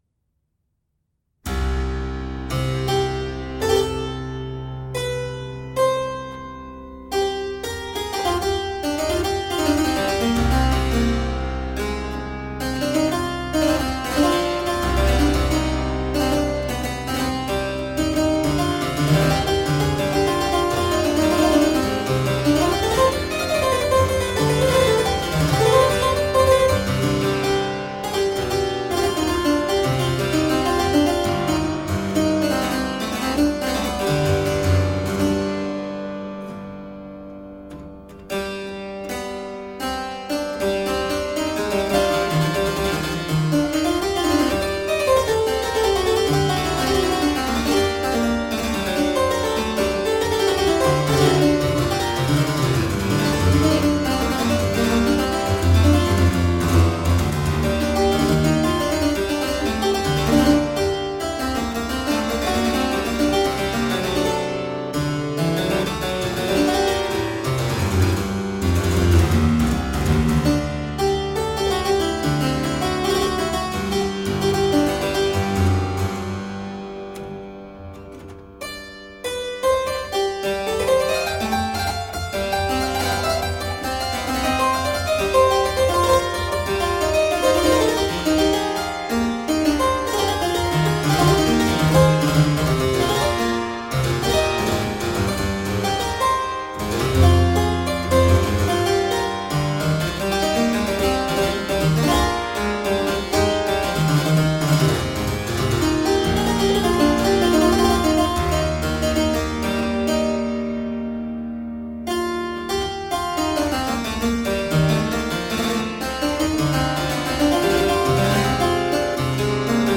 Vibrant harpsichord-music.
Classical, Baroque, Instrumental
Harpsichord